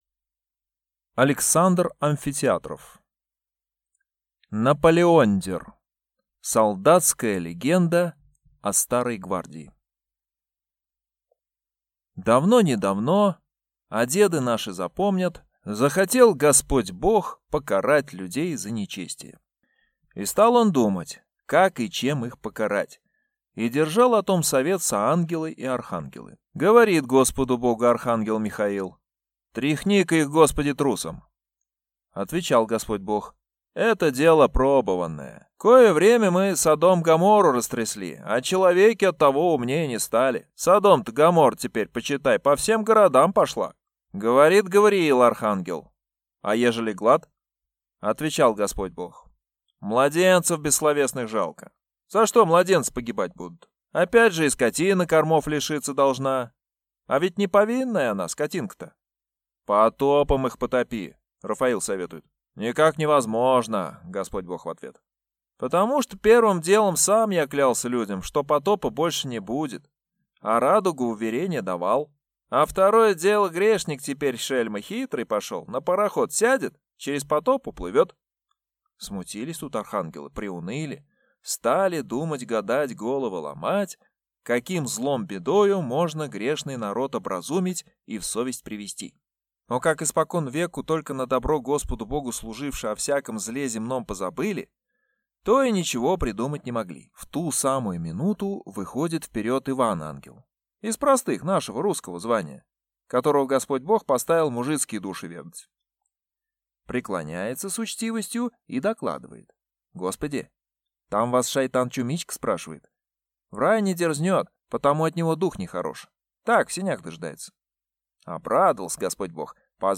Аудиокнига Наполеондер | Библиотека аудиокниг